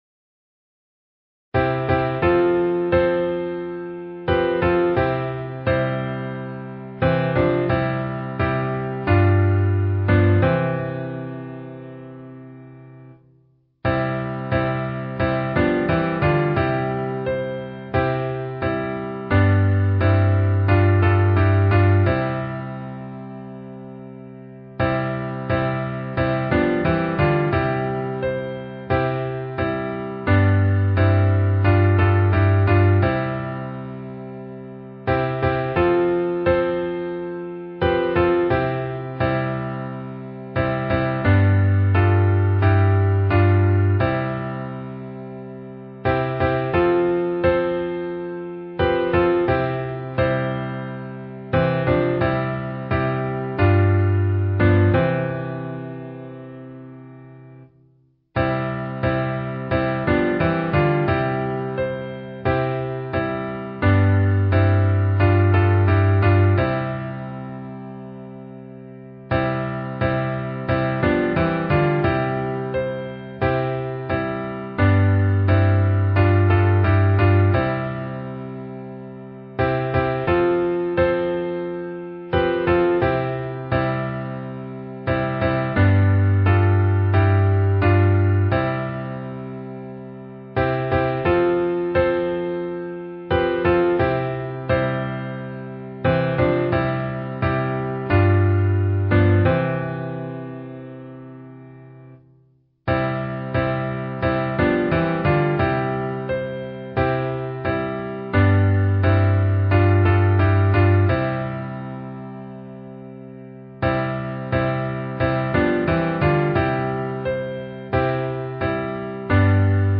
Key: D